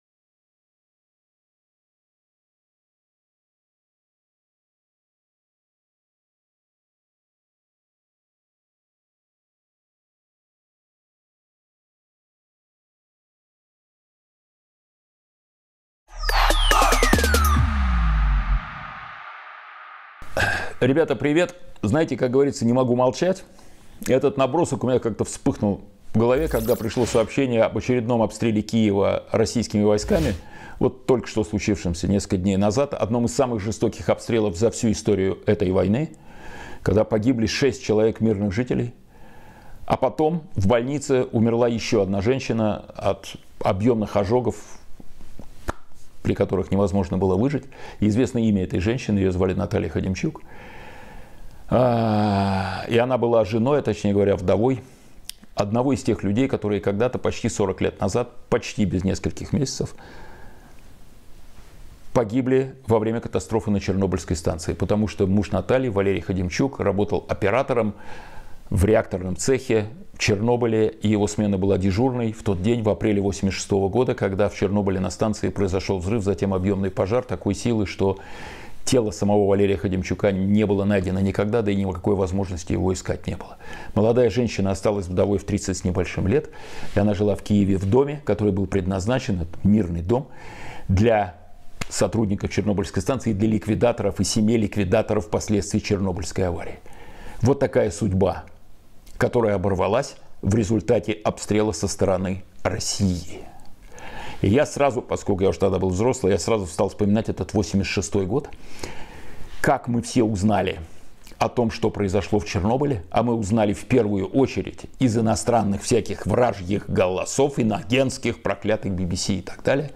Эфир ведёт Кирилл Набутов